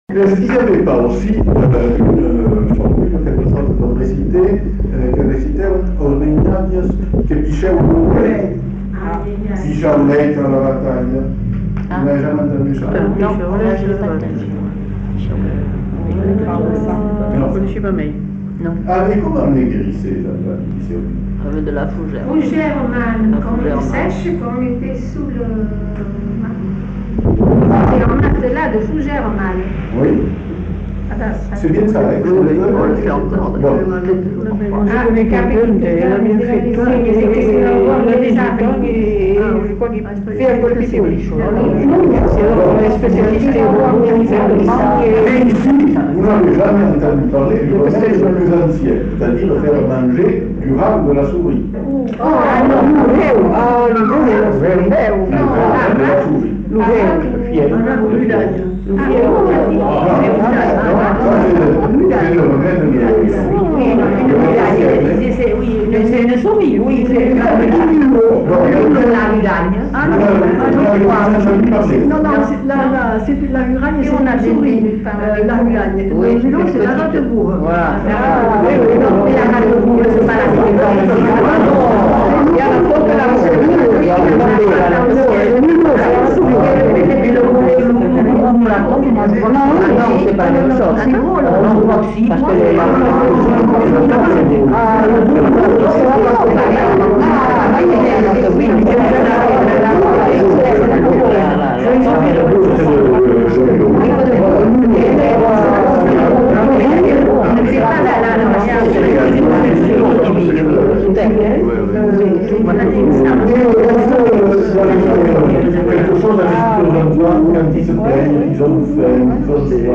Aire culturelle : Bazadais
Lieu : Bazas
Genre : témoignage thématique